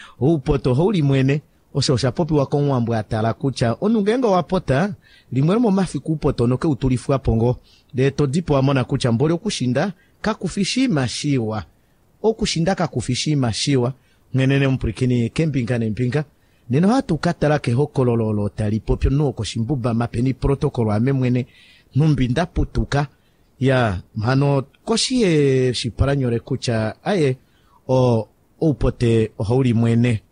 27 August 2017 at 7:14 am Syllable timing. Five vowels with some diphthongs. Possibly a few ejectives.